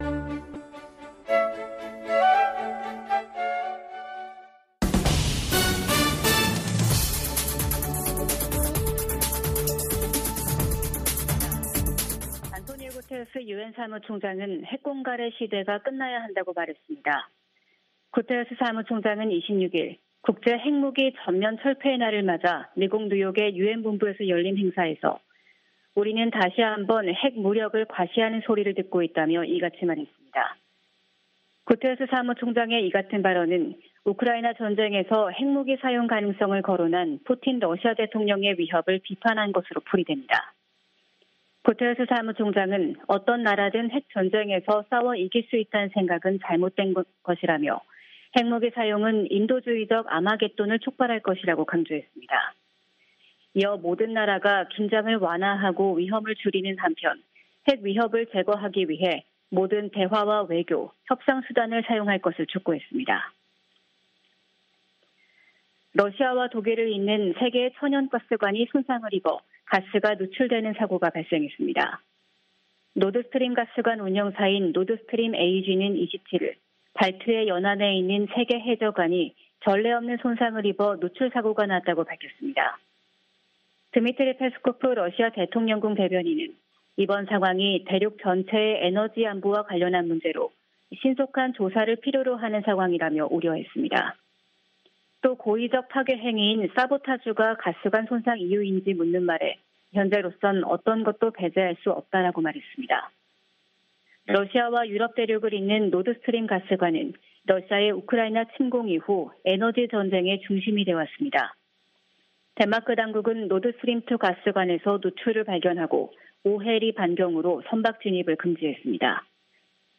VOA 한국어 아침 뉴스 프로그램 '워싱턴 뉴스 광장' 2022년 9월 28일 방송입니다. 미 국무부가 북한 정권의 어떤 도발도 한국과 일본에 대한 확고한 방어 의지를 꺾지 못할 것이라고 강조했습니다. 카멀라 해리스 미 부통령과 기시다 후미오 일본 총리가 회담에서 북한 정권의 탄도미사일 발사를 규탄했습니다. 북한이 핵 개발에 쓴 전체 비용이 최대 16억 달러에 달한다는 분석 결과가 나왔습니다.